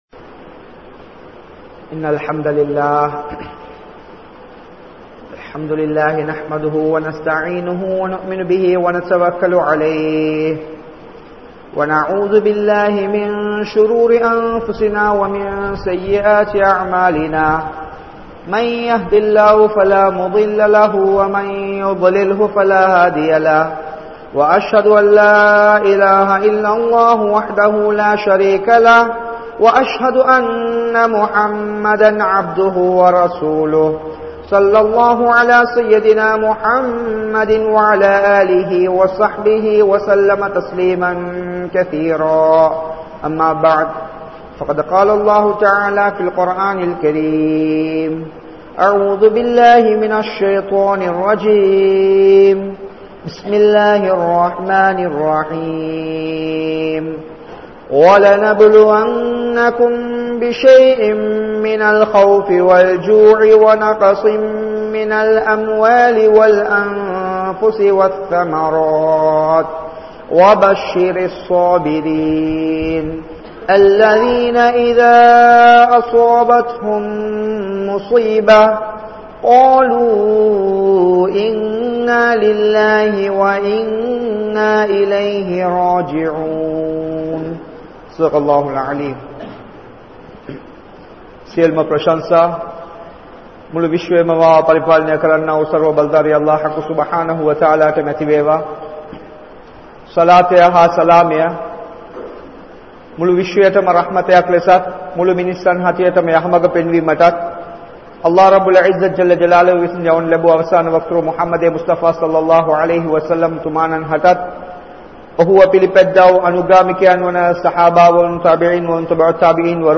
Porumai (பொறுமை) | Audio Bayans | All Ceylon Muslim Youth Community | Addalaichenai
Panadura, Gorakana Jumuah Masjith